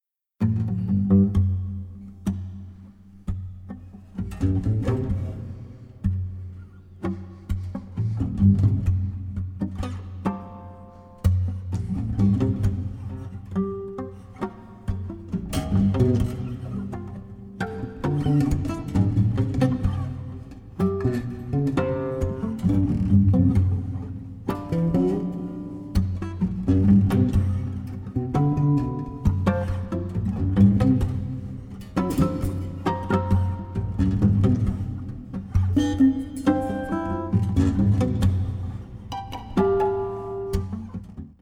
16-string classical guitar